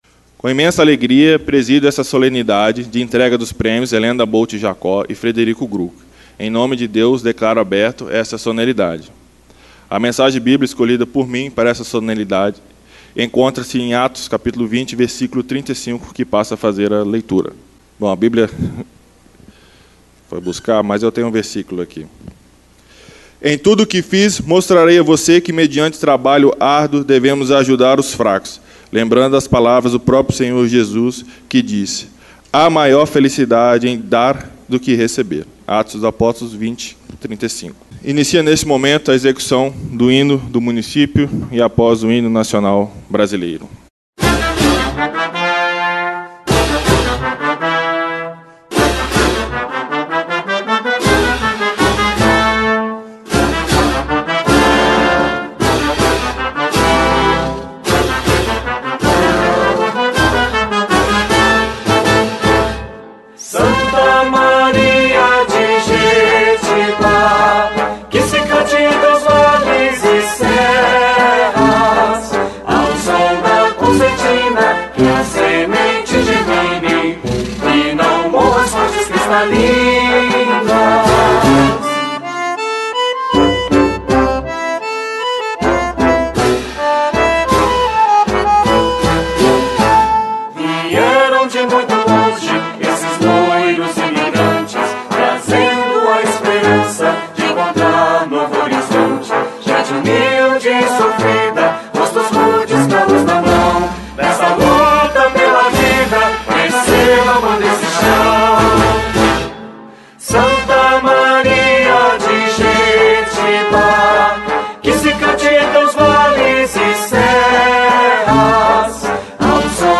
Hinos do Município e Nacional Brasileiro.
sessao-solene-no-3-2025